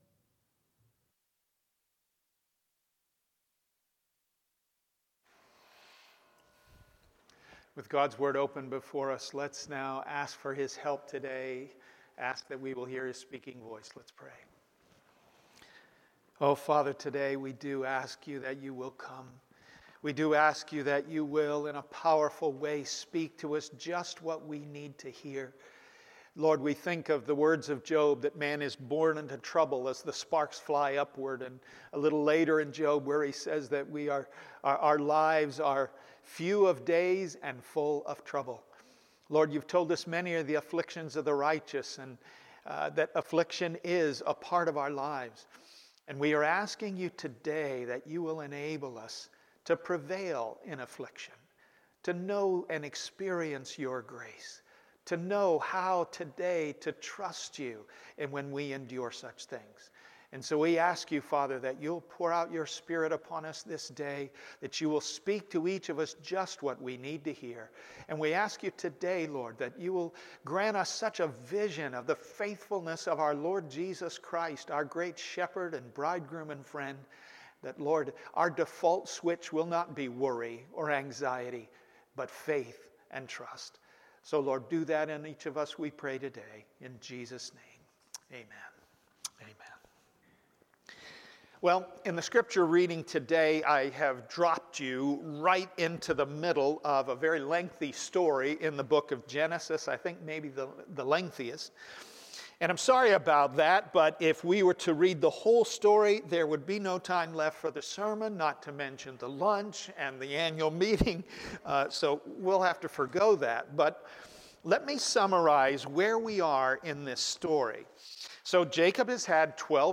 Passage: Genesis 42:36 Sermon